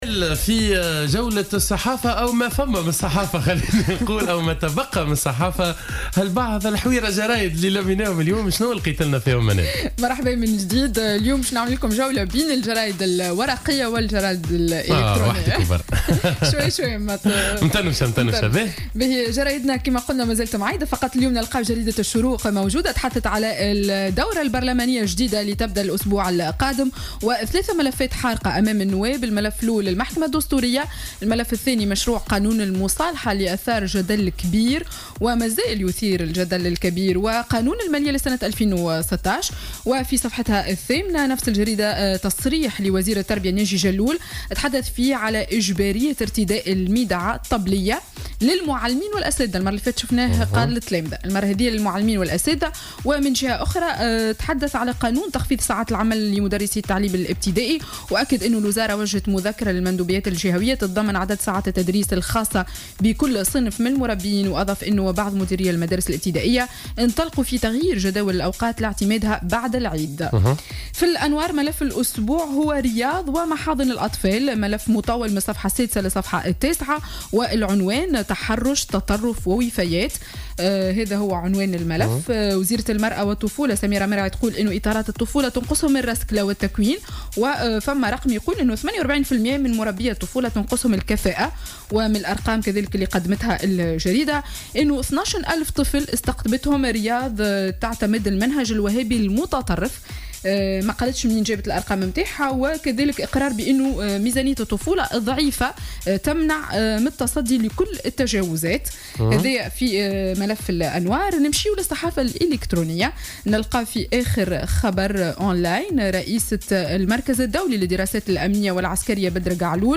Revue de presse du samedi 26 septembre 2015